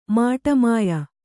♪ māṭamāya